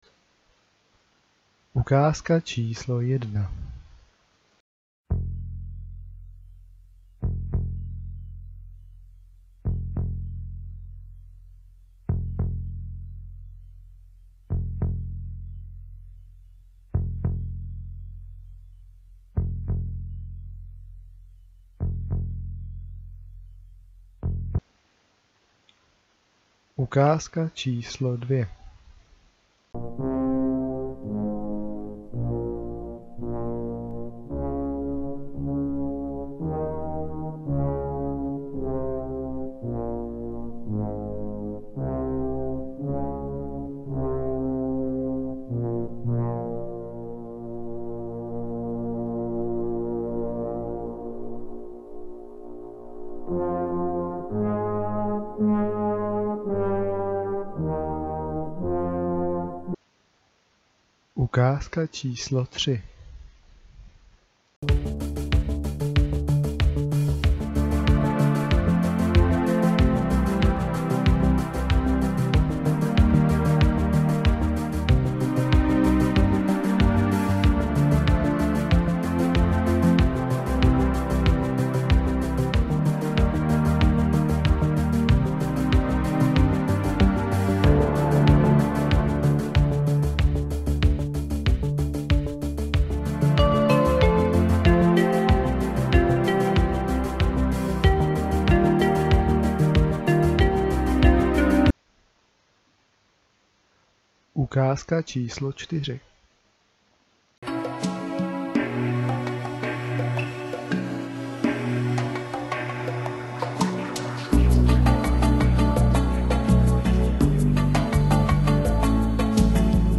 filmových melodií - poznej film / seriál.